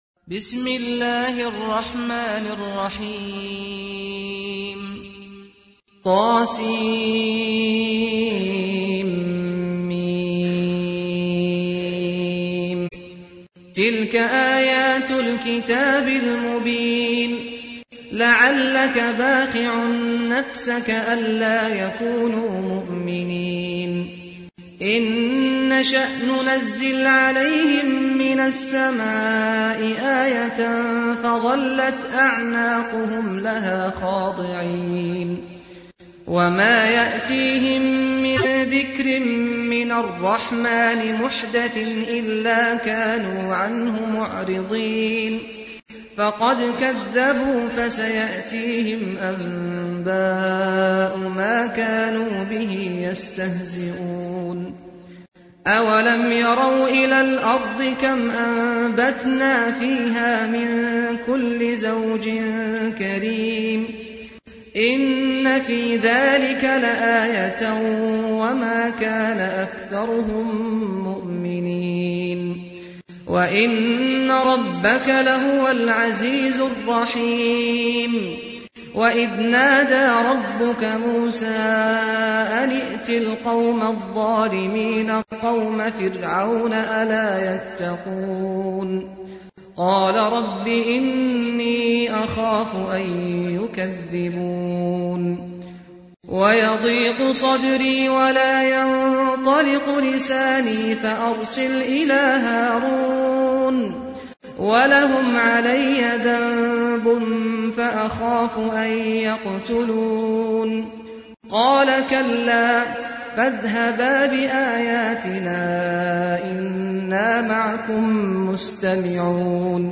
تحميل : 26. سورة الشعراء / القارئ سعد الغامدي / القرآن الكريم / موقع يا حسين